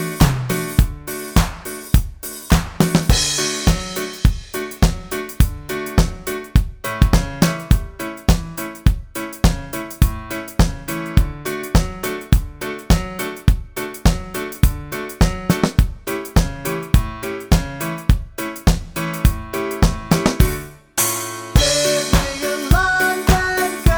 no bass T.V. Themes 3:42 Buy £1.50